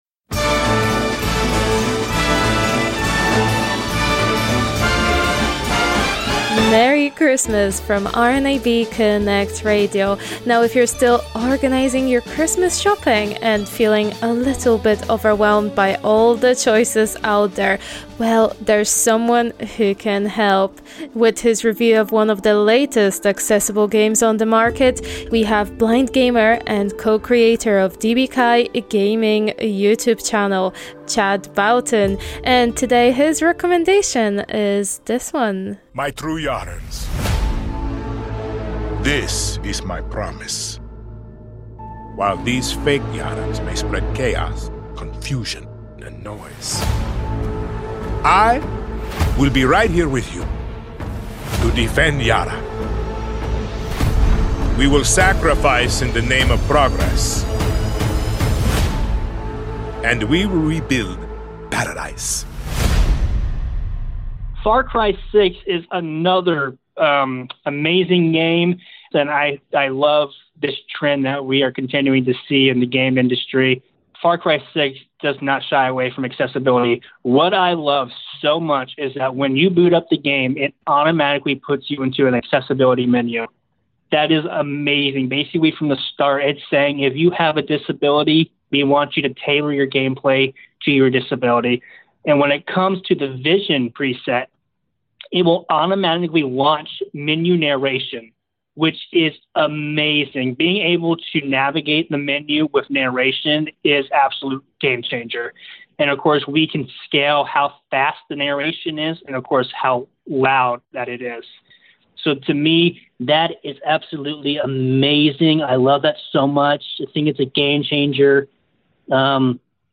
Christmas Accessible Game Review 3 - Far Cry 6